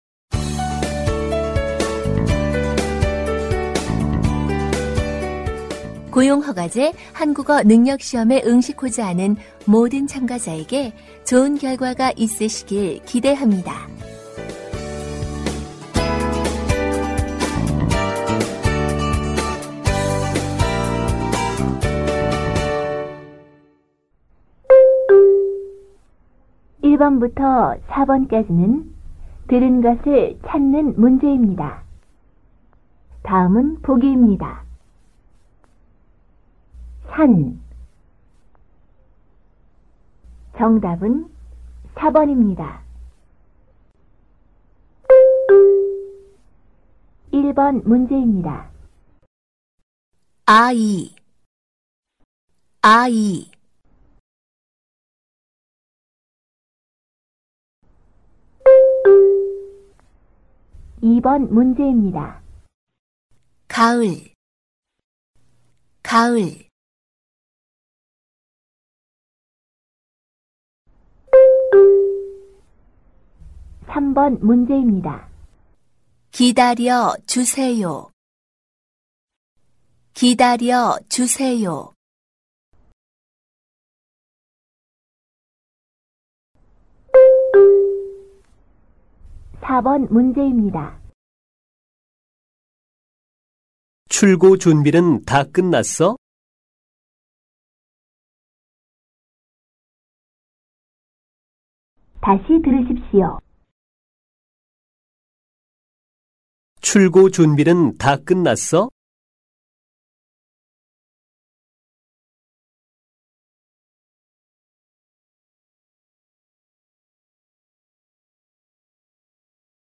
Phần Nghe Hiểu (듣기): Kéo dài từ câu 1 đến câu 25.
모든 듣기 문제는 두 번씩 들려 드립니다.